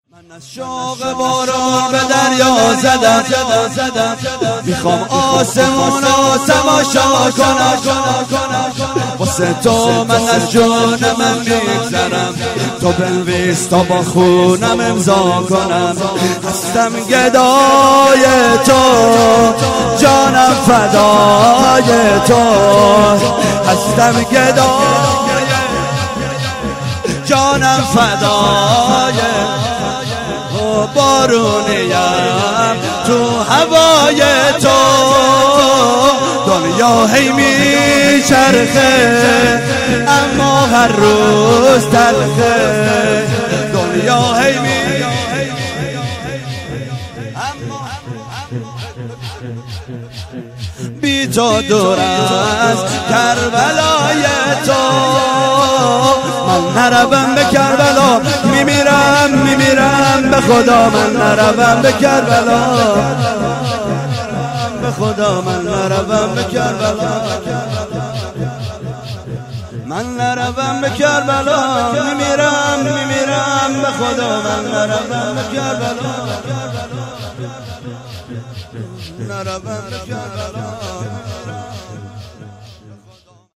شور - من از شوق بارون به دریا زدم
هفتگی - وفات حضرت ام البنین س - جمعه 11 اسفند